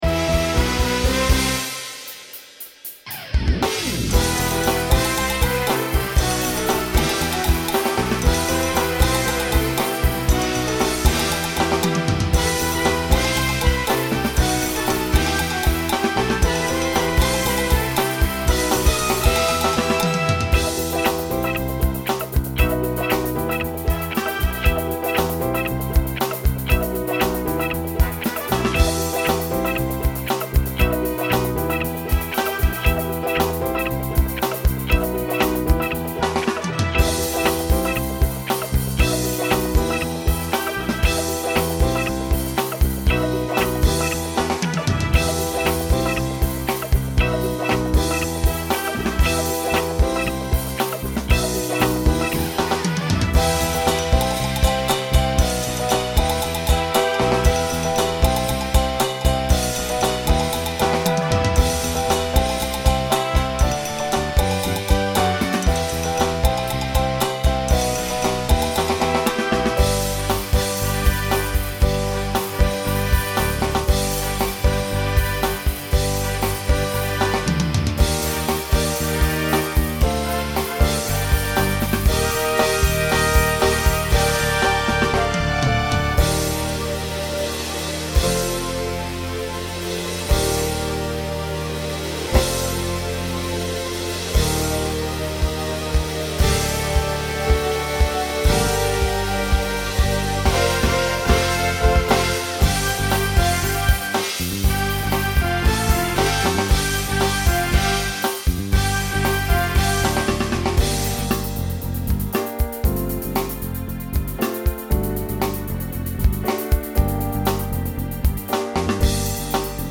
SSA/TTB
Voicing Mixed
Genre Pop/Dance